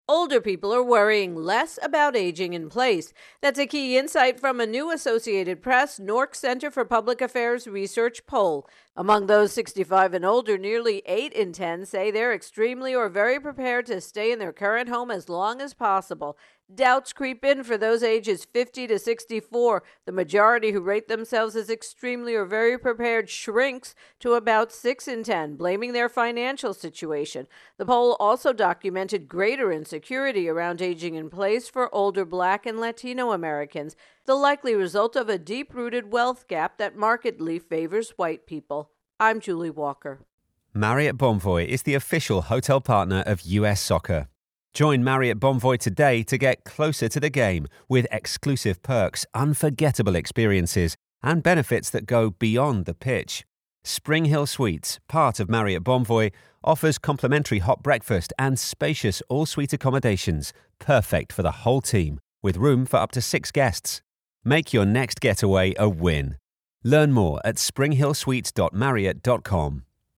AP Poll Aging in Place intro and voicer